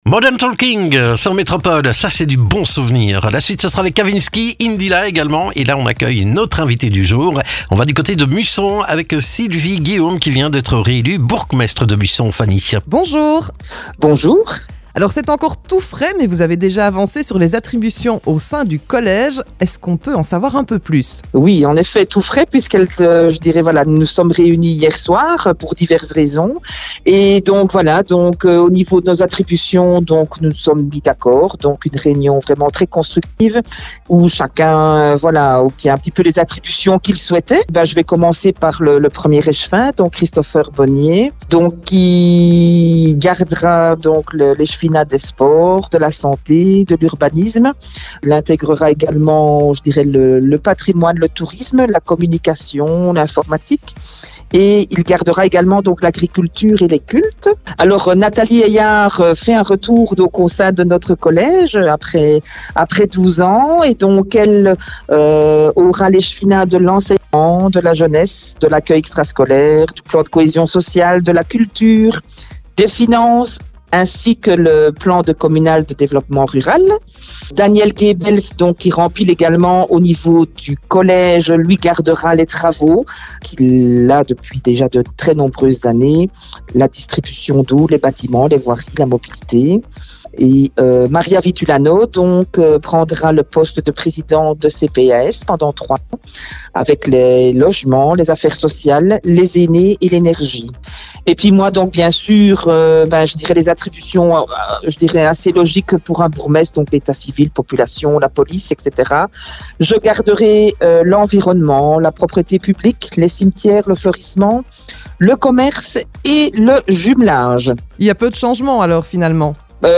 La bourgmestre réélue de Musson détaille avec nous les différentes attributions de son collègue communal et ses projets pour le futur.